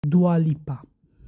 1. ^ Albanian: [ˈdua ˈlipa]